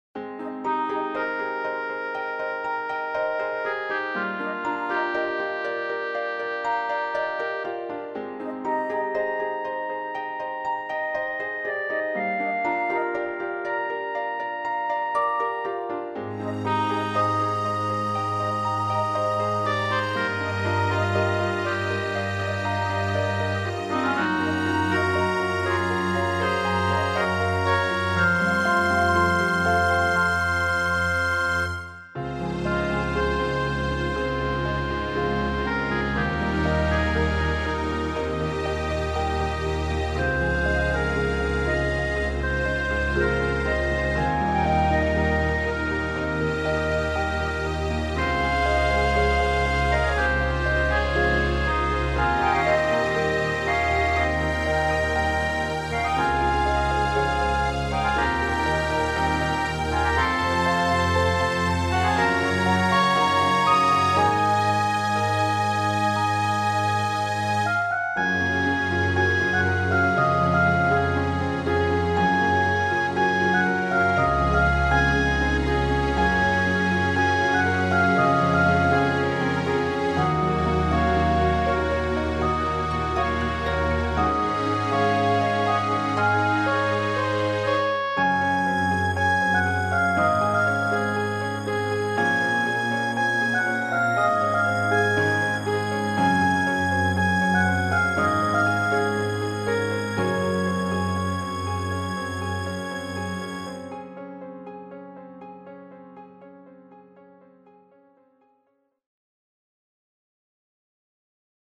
明るく爽やか曲。